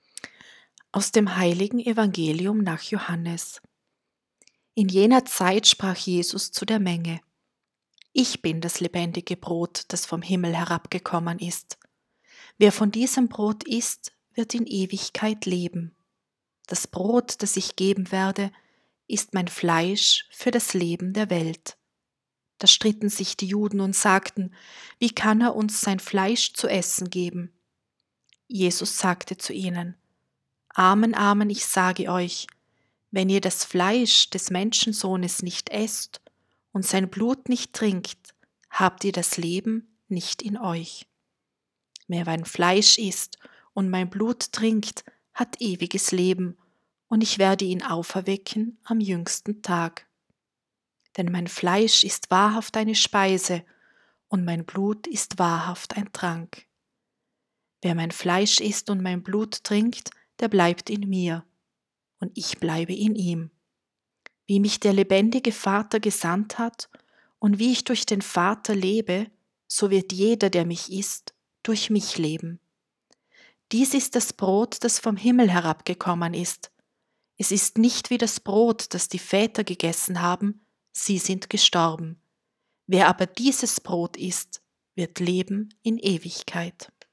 Evangelium-Fronleichnam.m4a